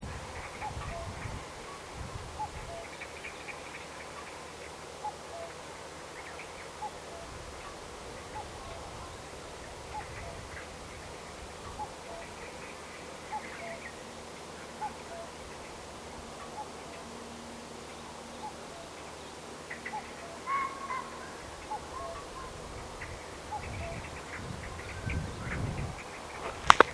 common_cuckoo.MP3